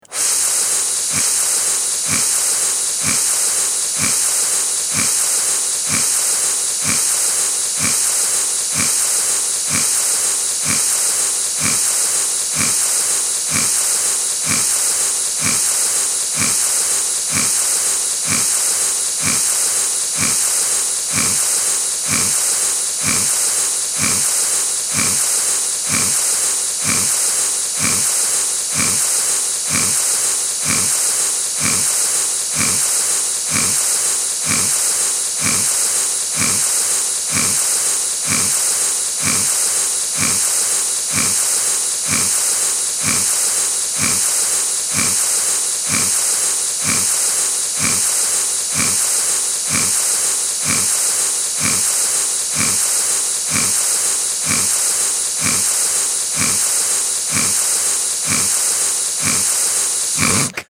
dbf-pink-noise.mp3